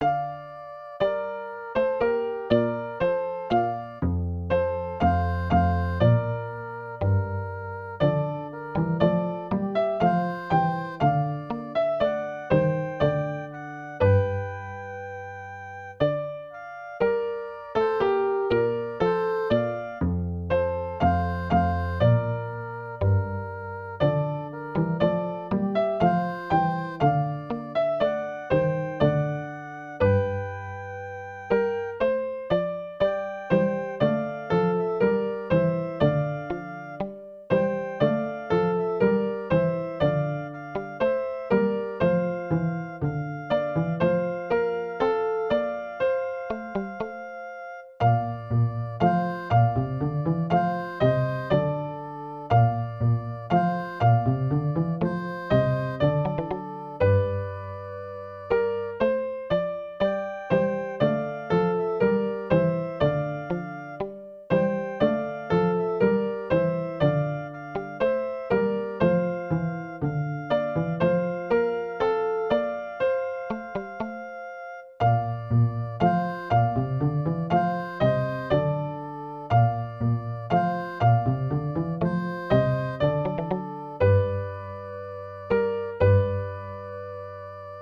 EDV-Simulation.